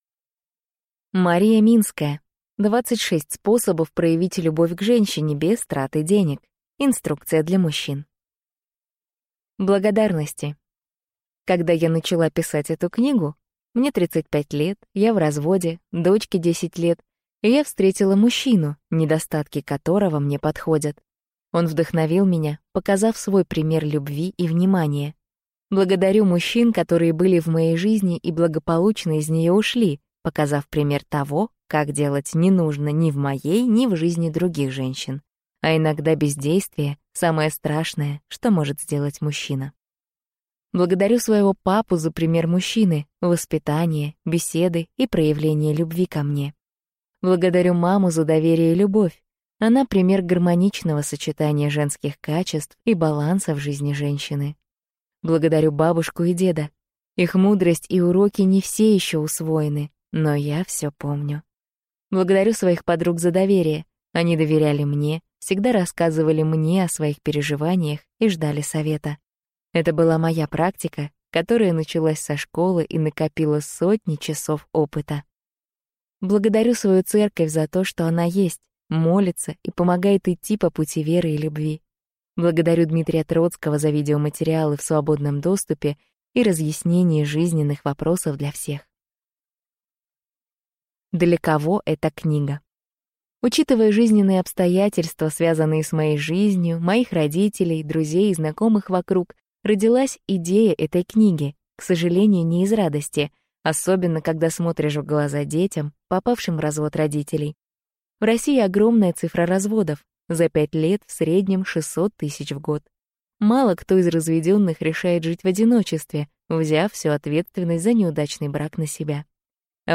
Аудиокнига 26 способов проявить любовь к женщине, без траты денег. Инструкция для мужчин | Библиотека аудиокниг